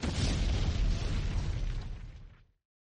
Tower Die.mp3